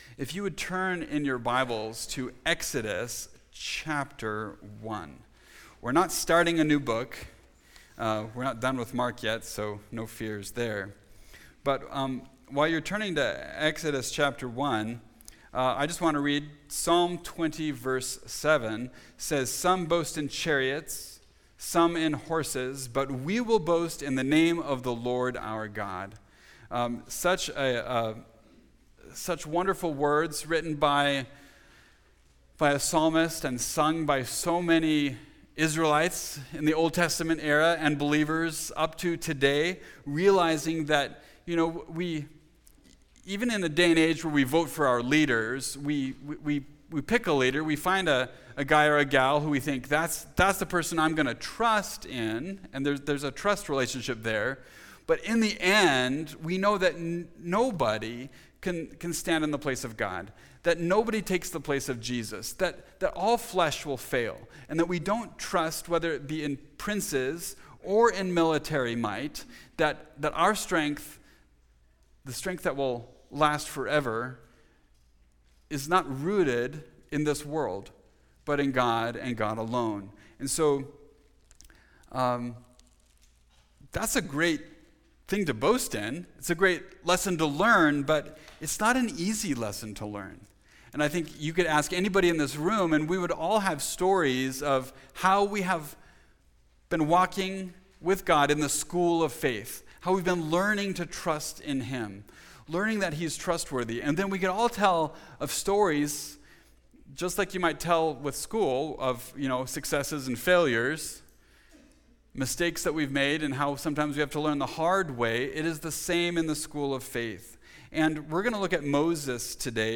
Moses: From Fear to Faith (Exodus 3-4) – Mountain View Baptist Church
Topical Message